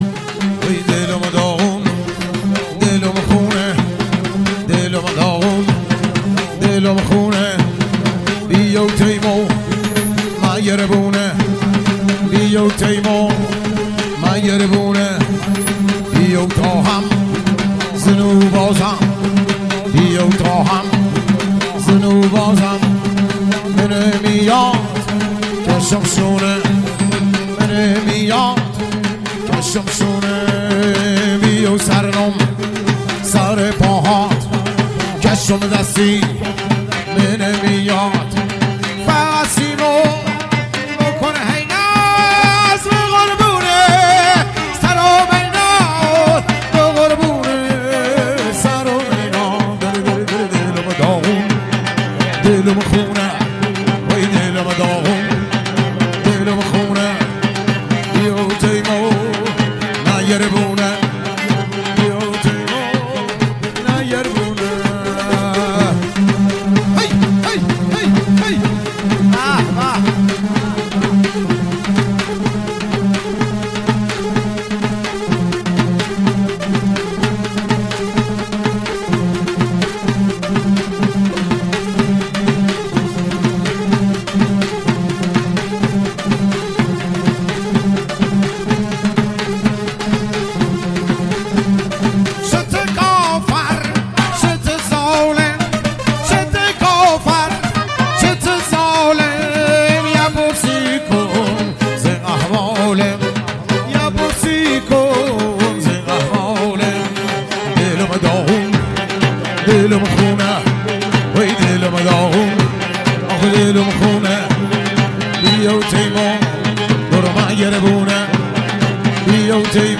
شاد رقص محلی لری